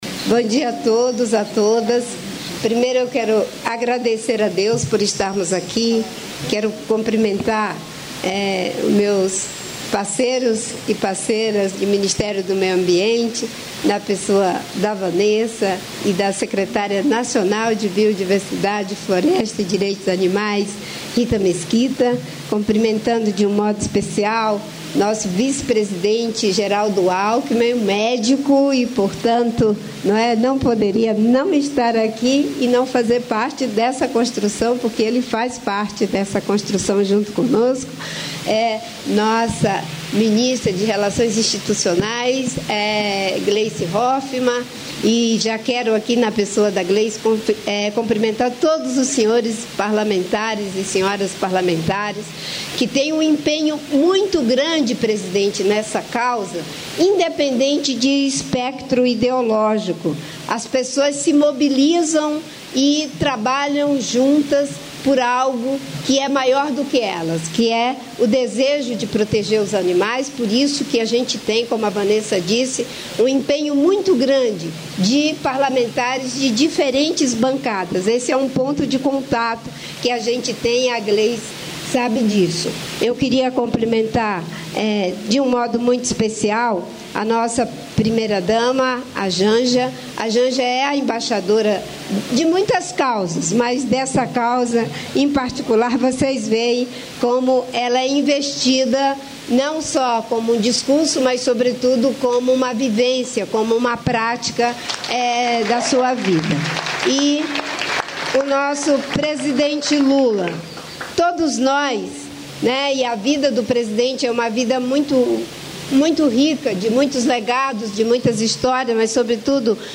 Íntegra do discurso do presidente da República em exercício, Geraldo Alckmin, durante cerimônia de lançamento da pedra fundamental da indústria de celulose Arauco, nesta quarta-feira (9), em Inocência (MS).